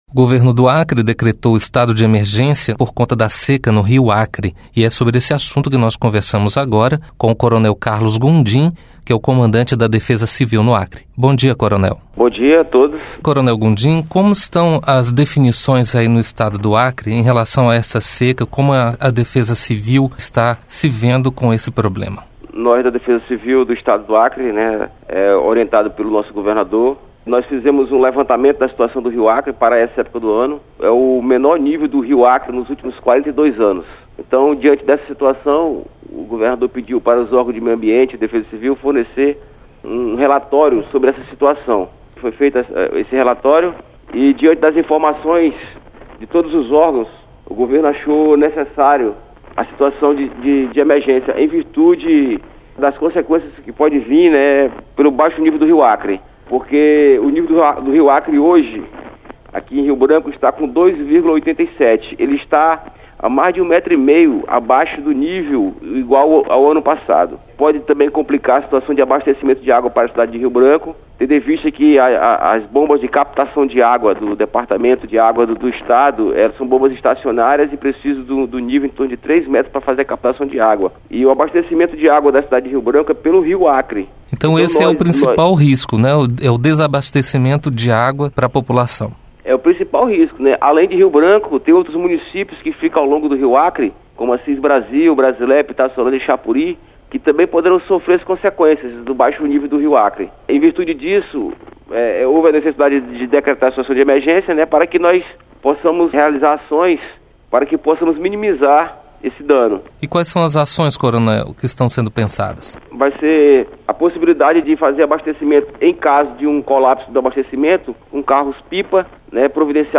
Entrevista: Os efeitos da seca no Rio Acre